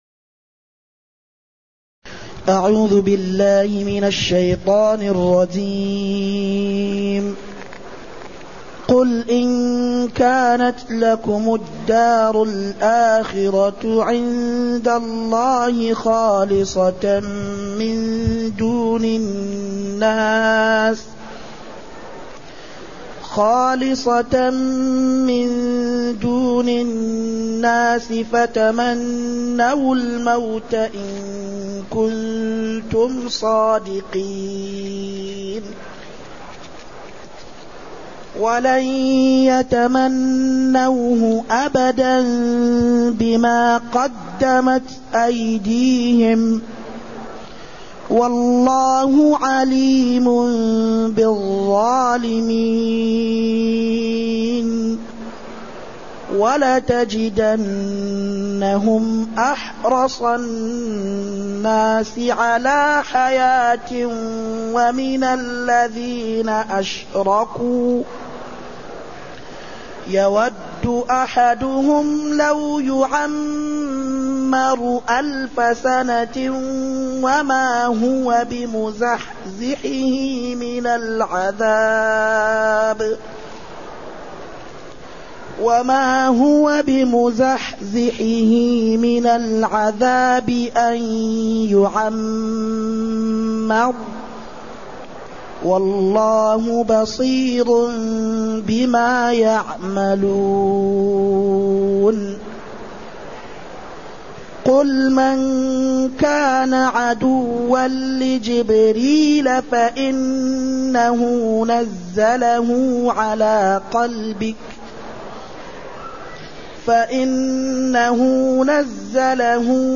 تاريخ النشر ٢٨ محرم ١٤٢٨ هـ المكان: المسجد النبوي الشيخ